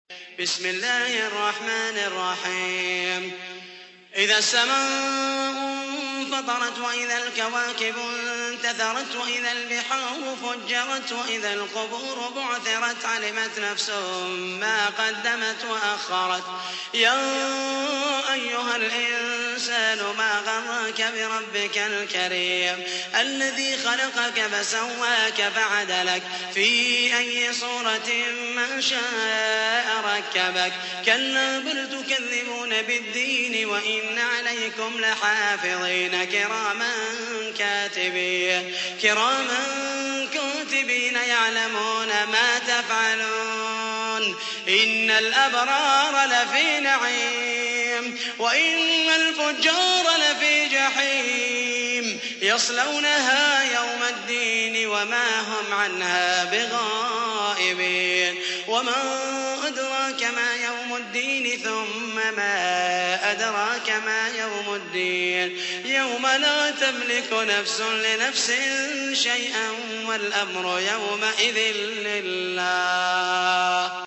تحميل : 82. سورة الانفطار / القارئ محمد المحيسني / القرآن الكريم / موقع يا حسين